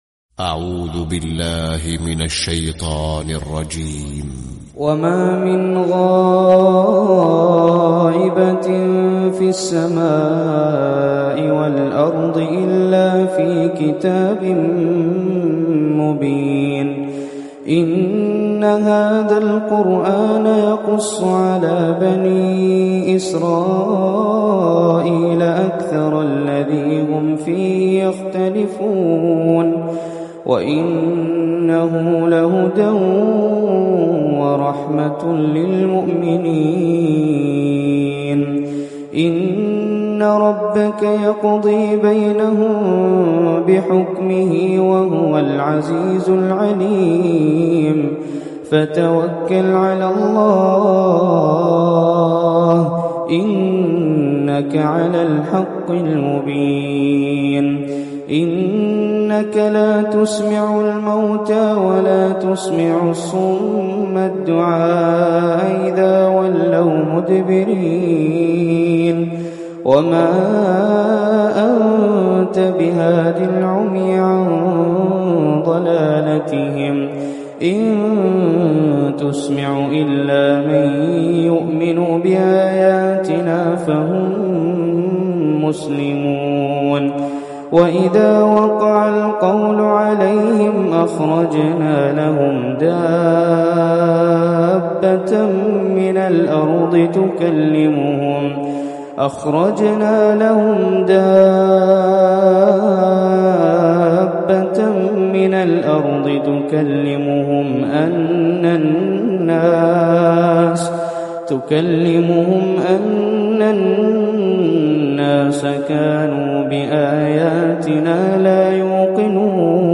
🕋🌻•تلاوة مسائية•🌻🕋
🎙 القارئ : هزاع البلوشي